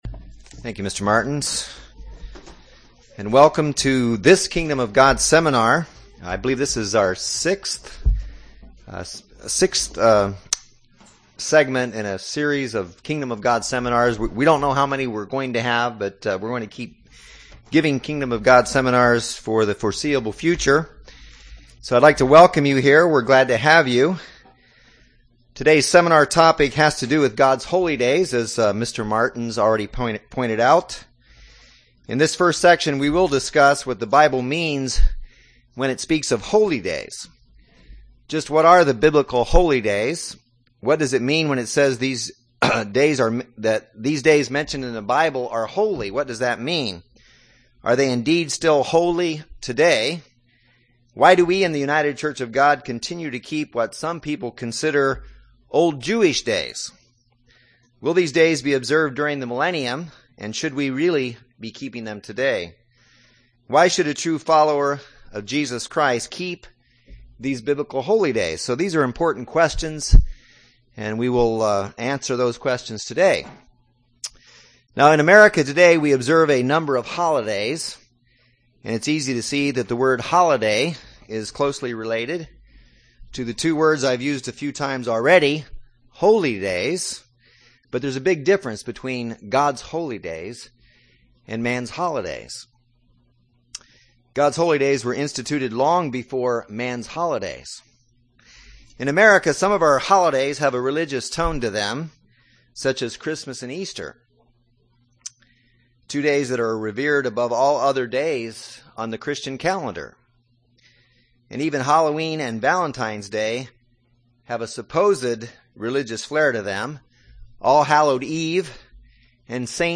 This first session of the Kingdom of God Bible Seminar, entitled, "God's Holy Day Plan," will be used to answer the title question by showing how God clearly established His Holy Days as revealed in the Bible, including what days are actually holy to Him. Also, the factors that make a day or time period holy to God will be discussed.